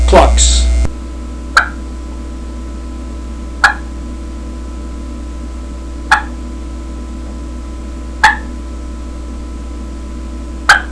Listen to 11 seconds of clucks
• QB Premium Green Latex, designed for crisp, raspy sounds and long life.
• Makes deep, raspy yelps, cackles, clucks, and cutts at all volume levels.
qbgrworldchampclucks11.wav